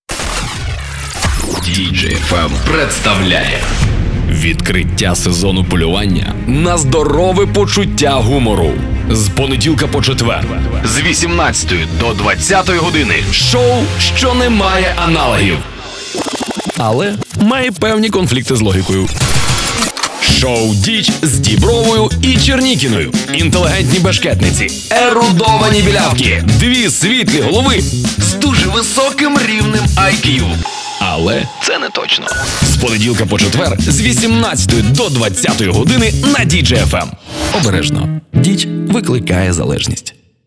Начитую рекламні ролики.
Теги: Announcer, Audio_Production, Host Of Programs